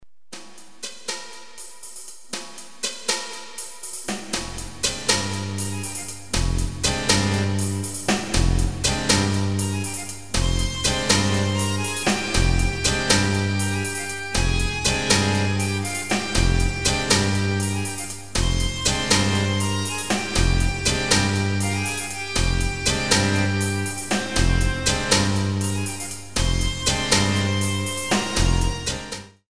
13 InstrumentalCompositions expressing various moods.